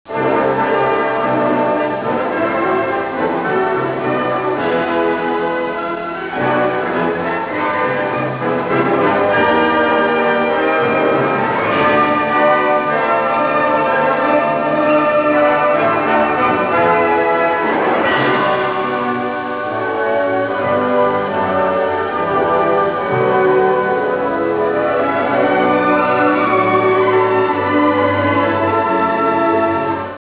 Colonna sonora
Original track music